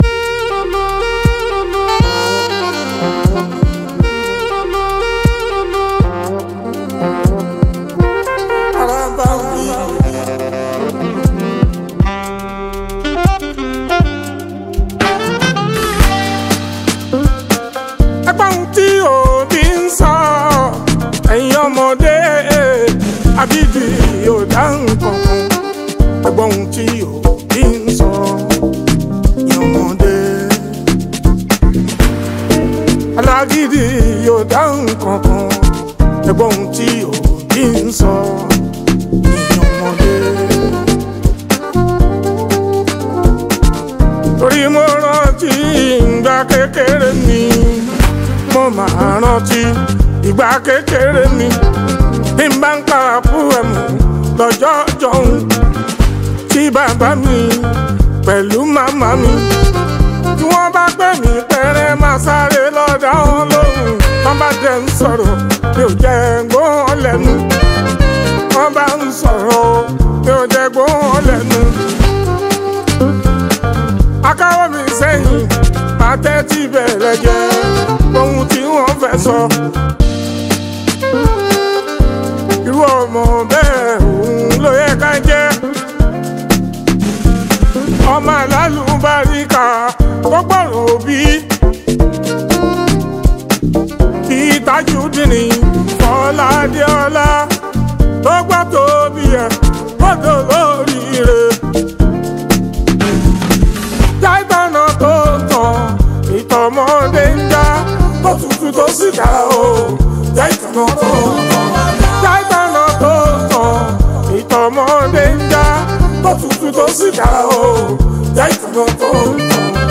Fuji, Highlife
Nigerian Yoruba Fuji track